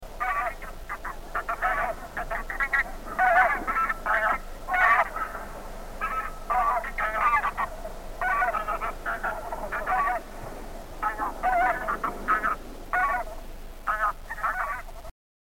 Основные признаки отличия гусей по голосовым признакам - " Гусь гуменник"